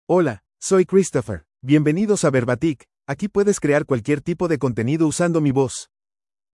MaleSpanish (United States)
ChristopherMale Spanish AI voice
Christopher is a male AI voice for Spanish (United States).
Voice sample
Christopher delivers clear pronunciation with authentic United States Spanish intonation, making your content sound professionally produced.